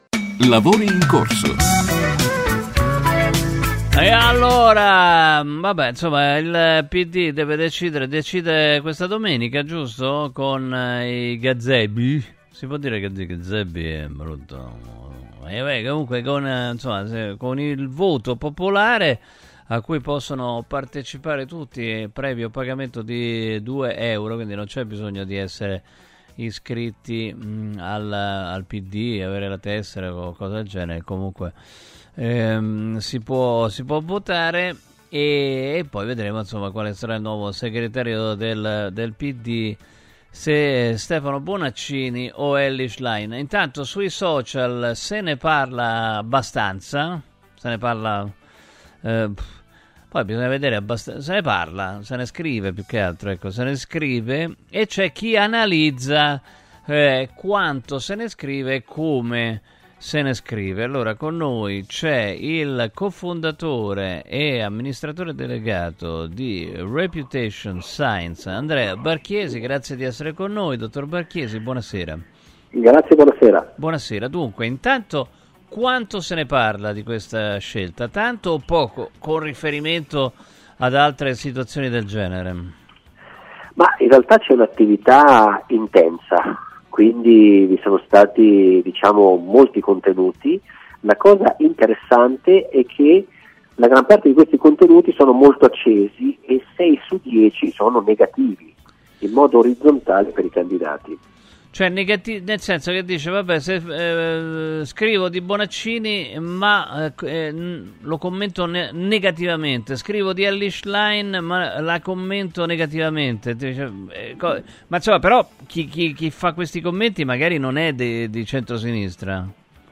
Nel corso del programma Lavori in corso su Radio Radio, ho parlato del sentiment generato online dai candidati alle primarie Pd.